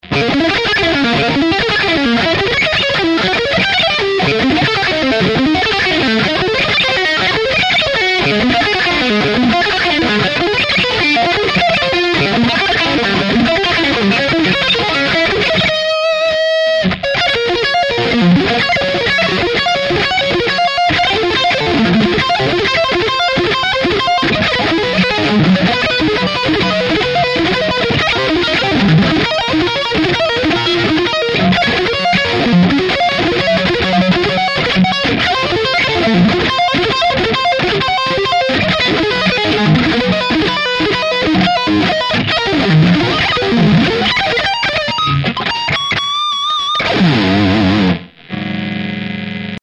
sloppy as hell, needs lotsa work....